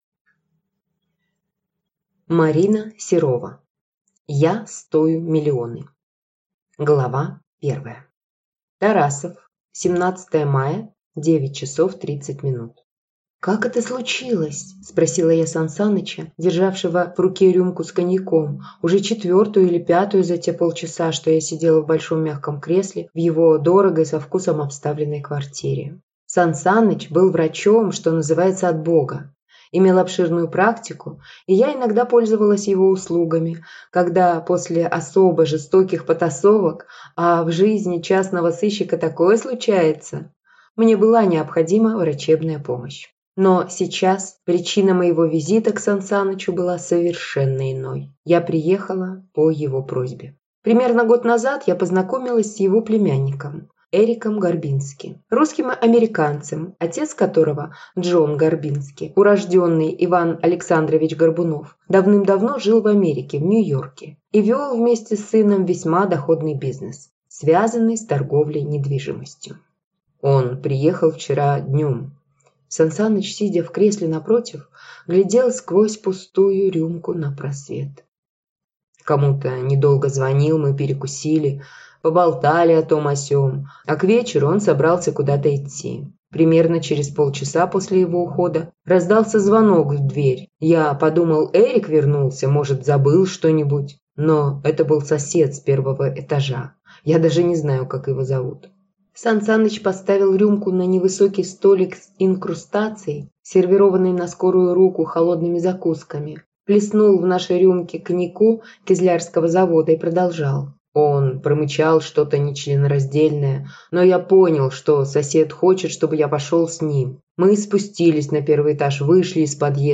Аудиокнига Я стою миллионы | Библиотека аудиокниг